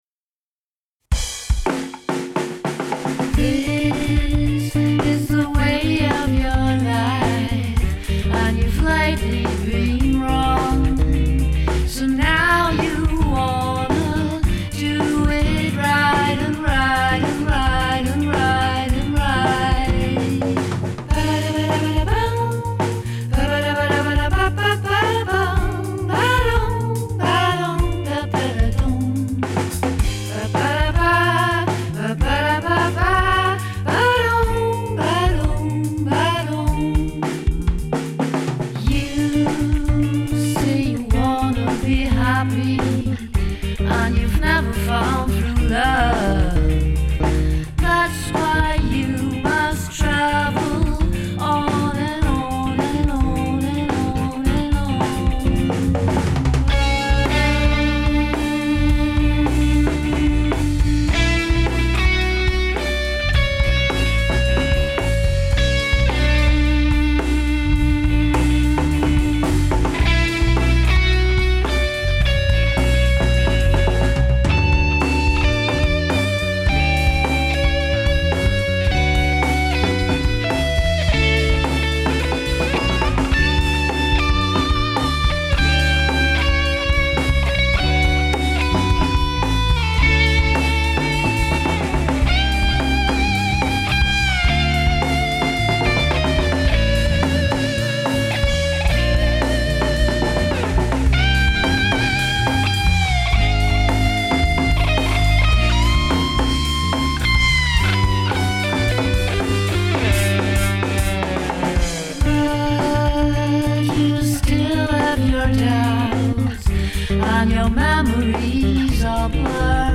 Hier sind Aufnahmen an denen ich mit meinem Bass beteiligt bin.
Gesang/Drums
Gitarre